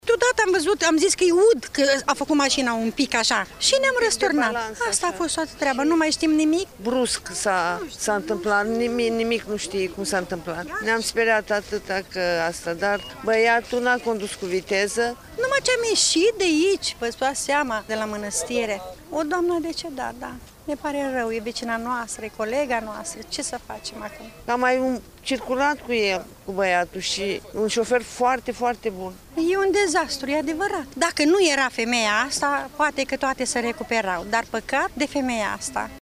Pasagerii din microbuz spun că totul s-a întâmplat într-o fracţiune de secundă:
7-oct-rdj-18-insert-pasageri.mp3